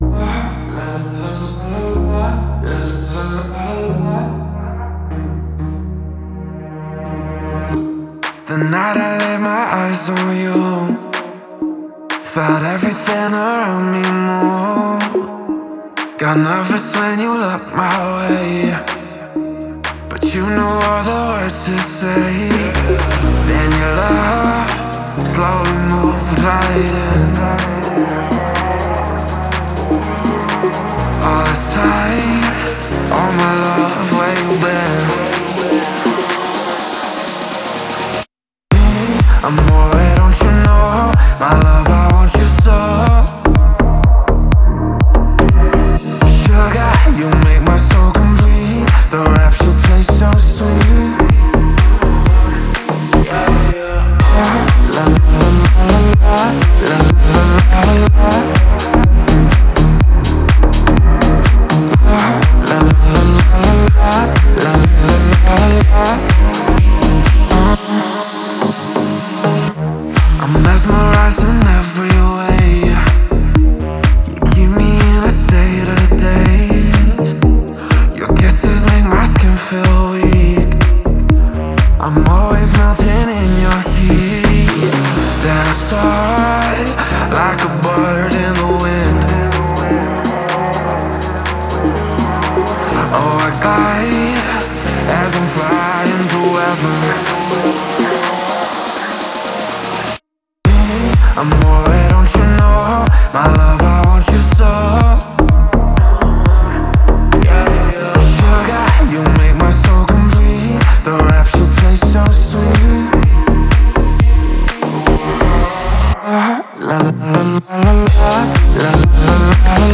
это динамичная трек в жанре EDM